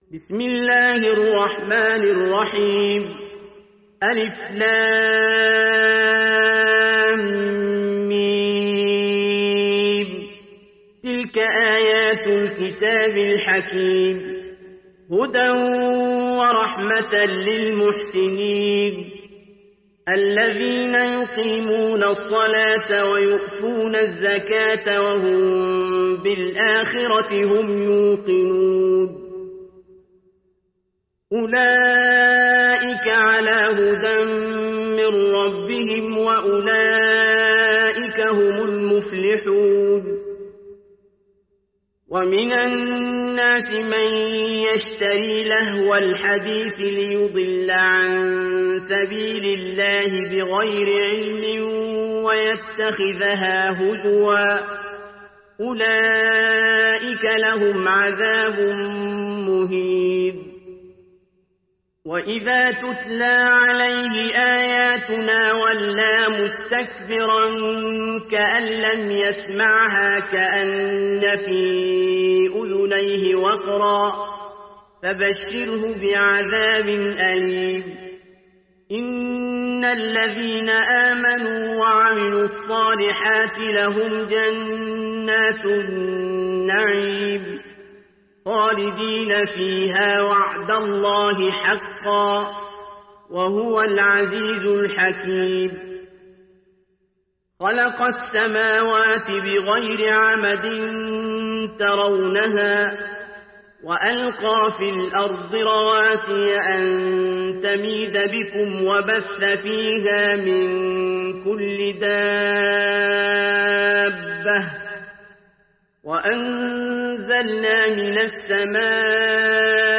Surat Luqman Download mp3 Abdul Basit Abd Alsamad Riwayat Hafs dari Asim, Download Quran dan mendengarkan mp3 tautan langsung penuh